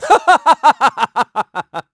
Riheet-Vox_Happy3_kr.wav